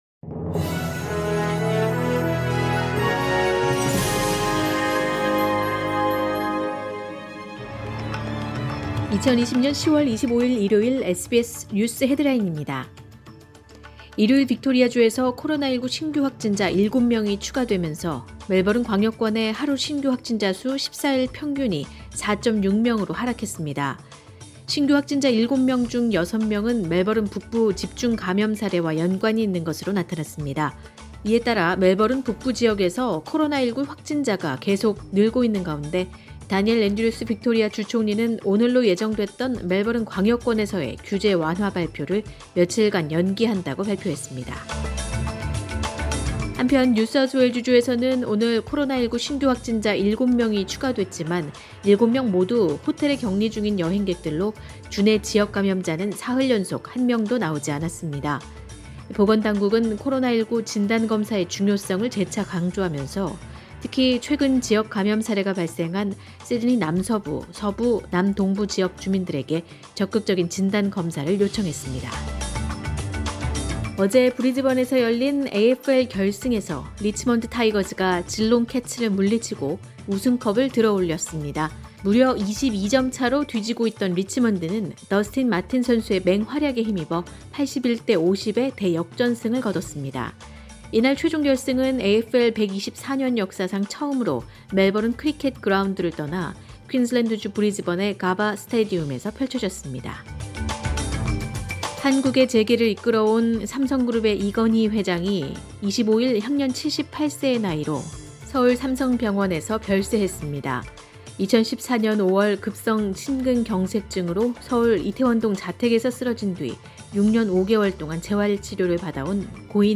2020년 10월 25일 일요일 오전의 SBS 뉴스 헤드라인입니다.